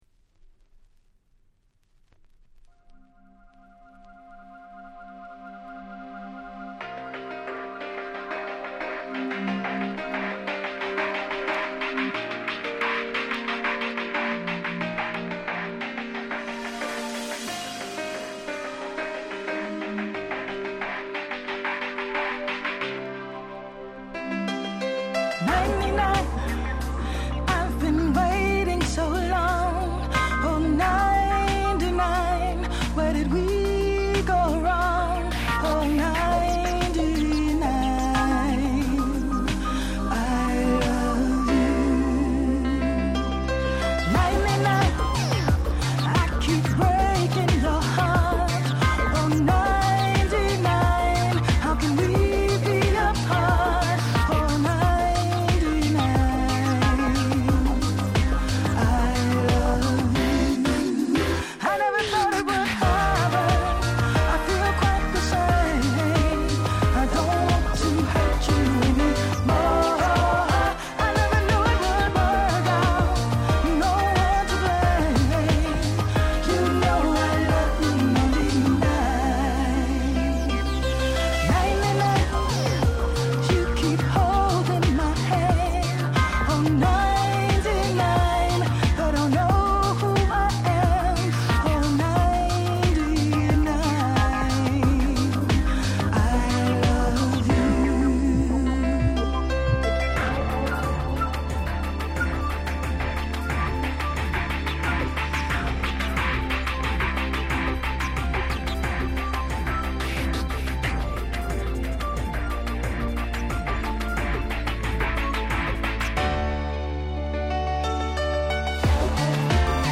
04' Nice Japanese House/R&B !!